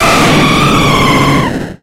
Cri de Lugia dans Pokémon X et Y.